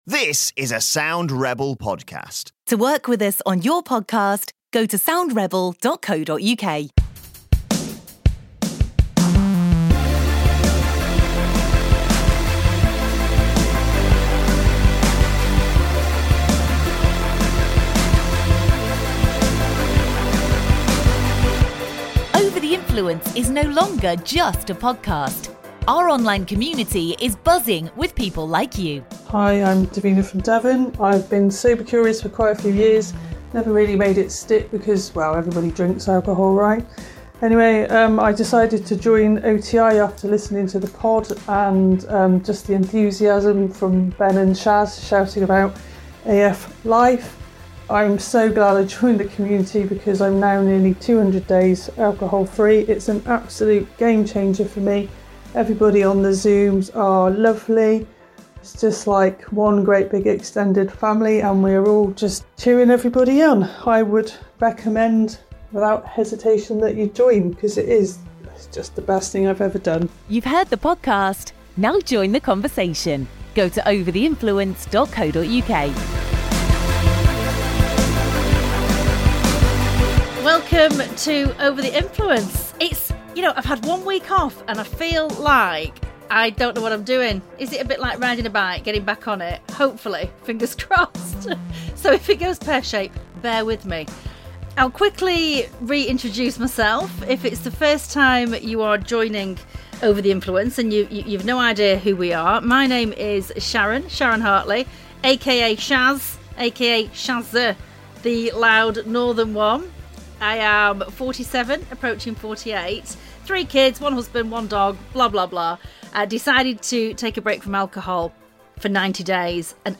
It's also fabulous to now have our Over The Influence community members watching the podcast as its recorded in our live studio (well, Zoom) audience!